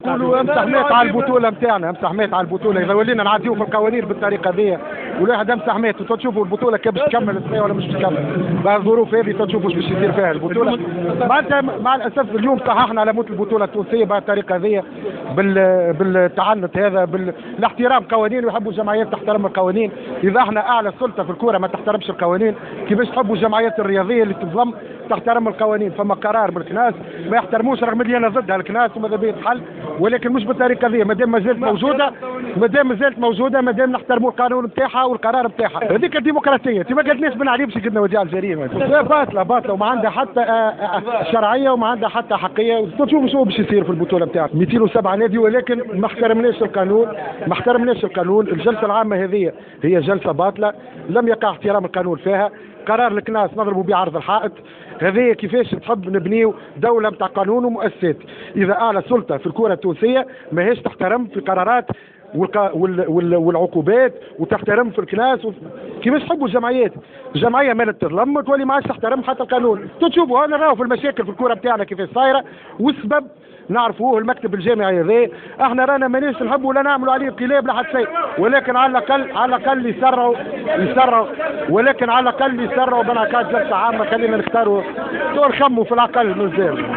تصريح لجوهرة أف أم